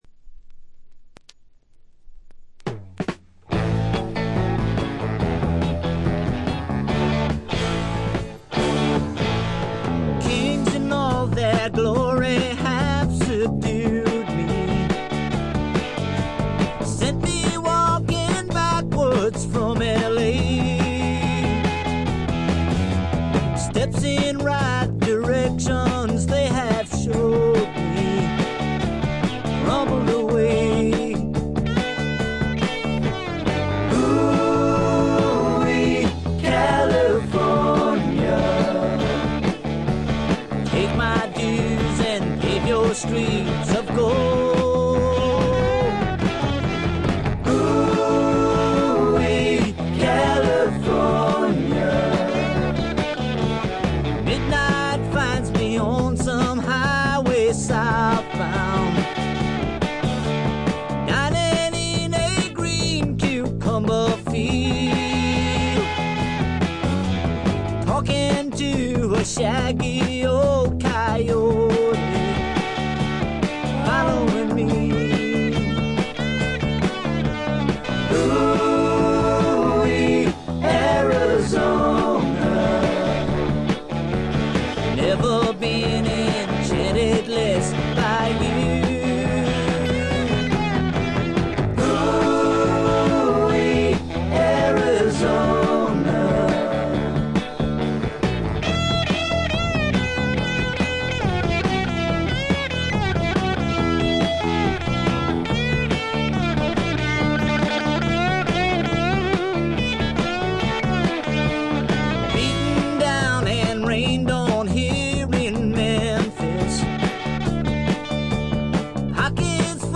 ホーム > レコード：カントリーロック
これ以外は軽微なバックグラウンドノイズ少々、軽微なチリプチ少々。
試聴曲は現品からの取り込み音源です。